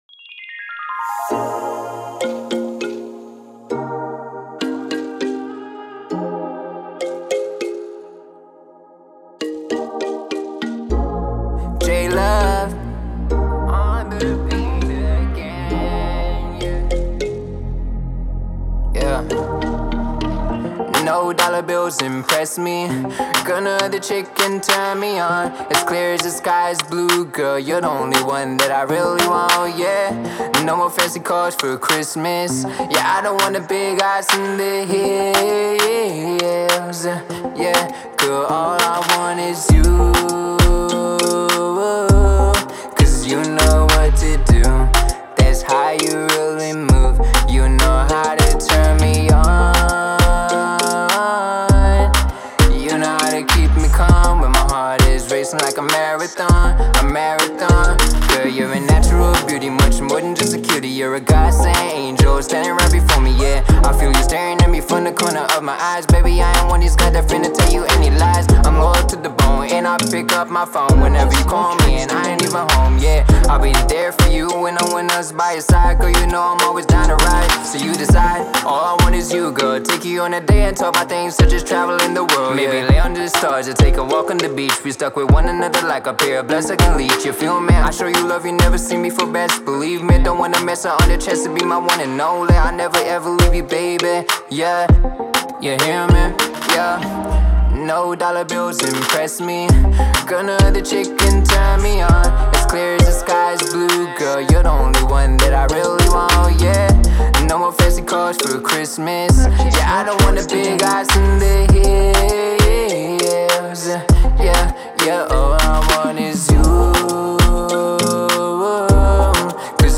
Singer/Songwriter